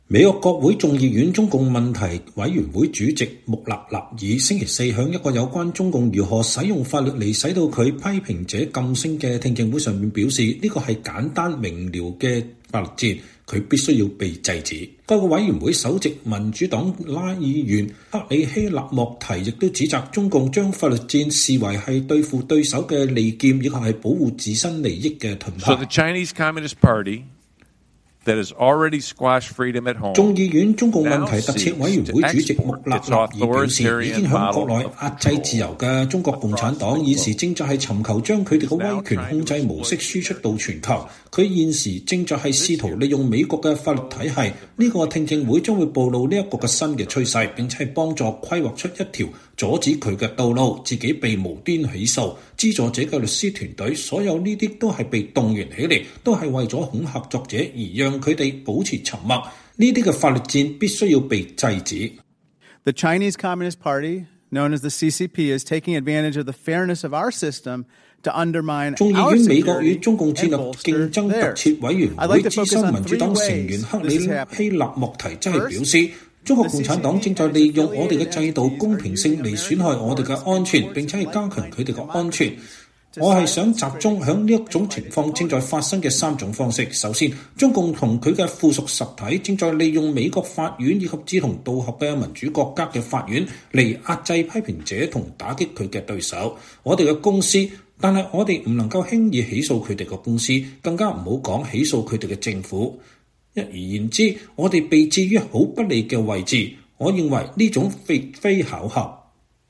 美國國會眾議院中共問題委員會主席約翰·穆勒納爾(John Moolenaar) 週四在一個有關中共如何使用法律來使其批評者噤聲的聽證會上說。“